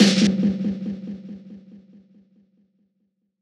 Same Old Song Snare Roll.wav